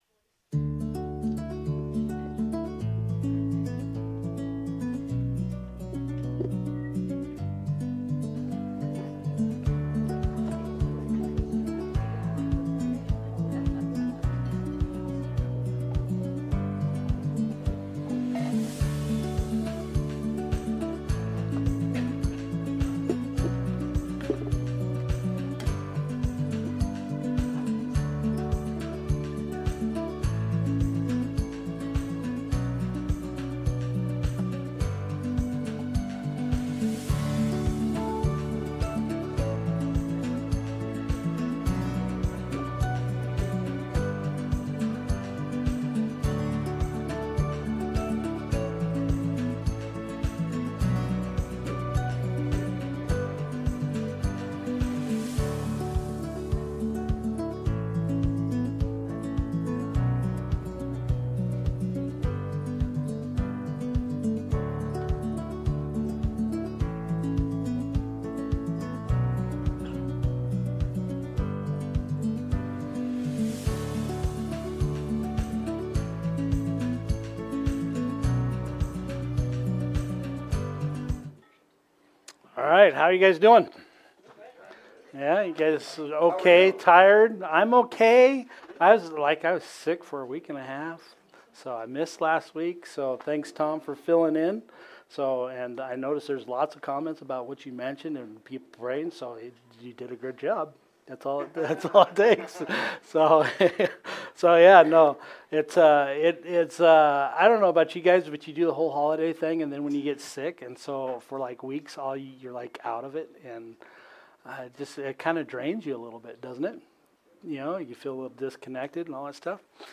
Jan 12 Sermon